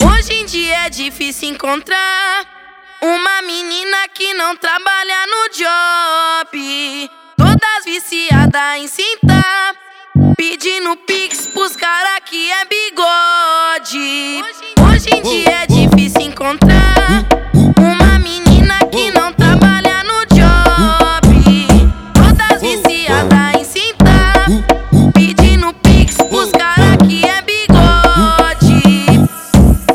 Жанр: Фанк
# Baile Funk